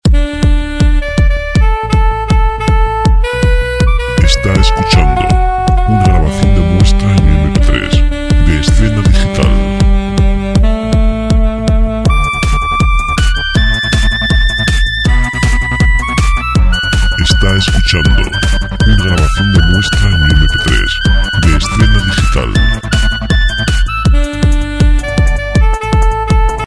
Royalty free dance music